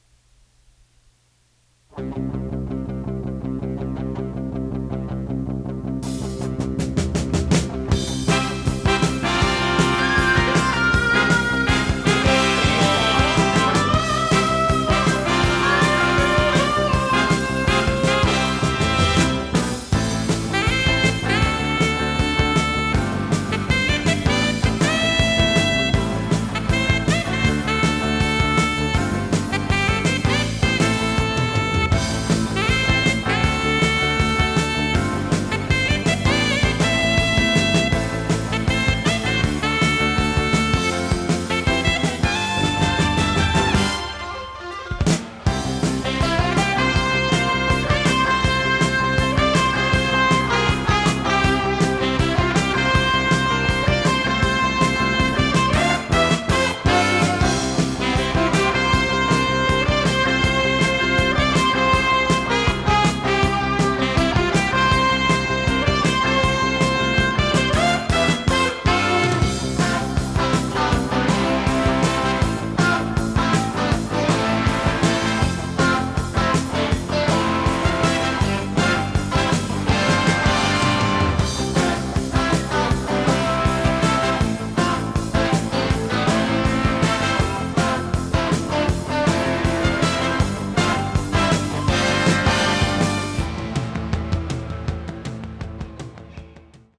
○周波数レンジは狭いが音質は素直
テープ：fox C-60
ノイズリダクションOFF
【フュージョン・ロック】容量26.6MB